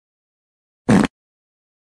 Sound Effects
Fart 6